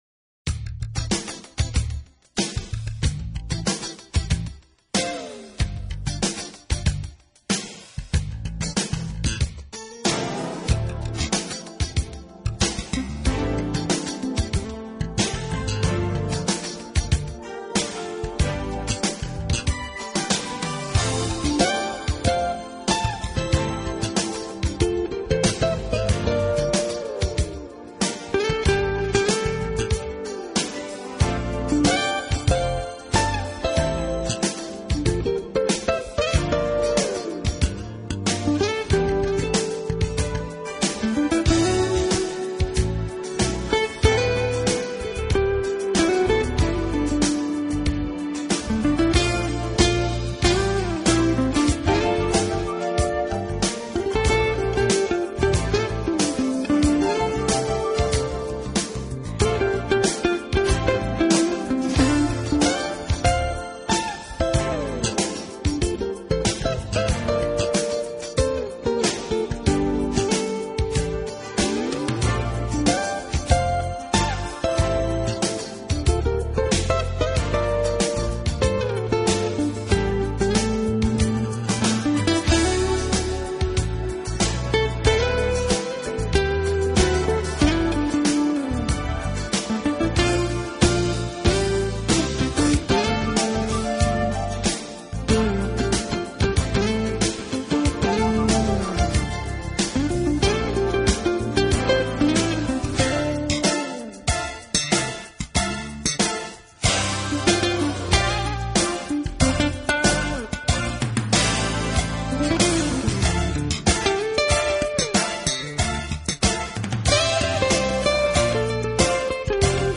他的風格是屬於Soft Jazz，一種輕鬆愉快弦律的融合爵士樂，自幼就生長在陽光普照